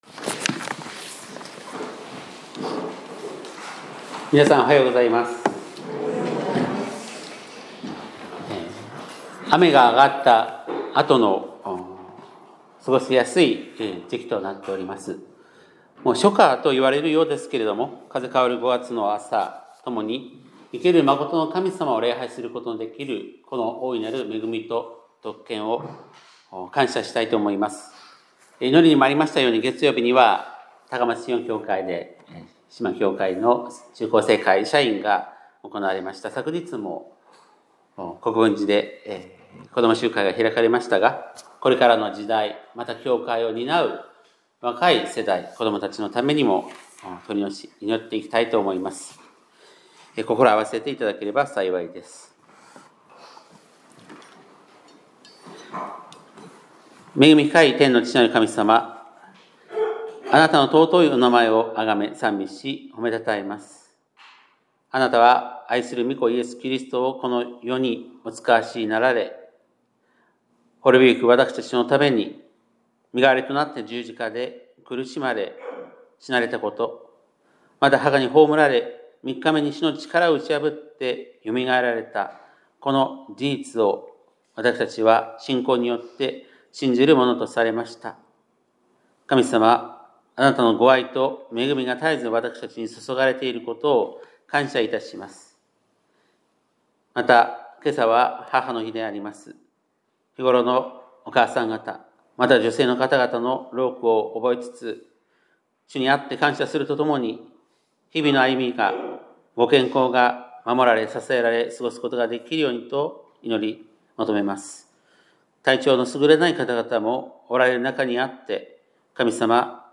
2025年5月11日（日）礼拝メッセージ